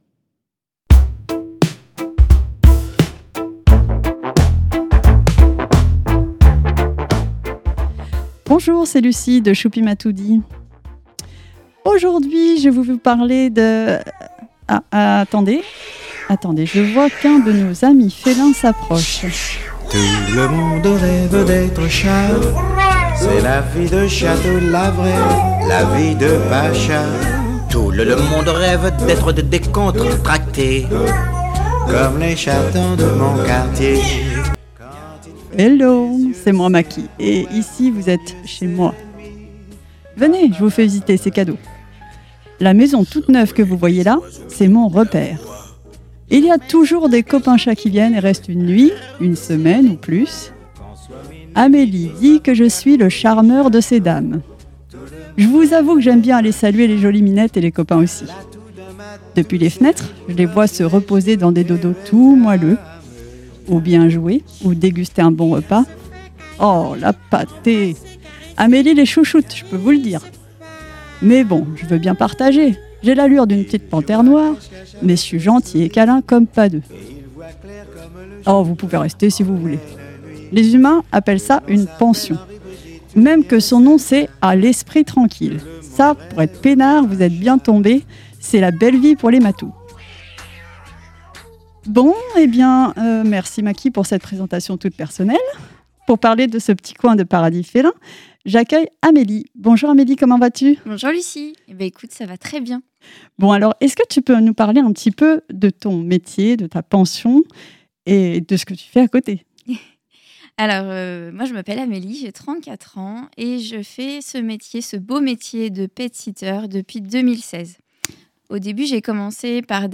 La chronique de la cause féline et animale de Radio G!